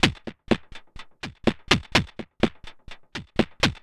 tx_perc_125_yuckbeat1.wav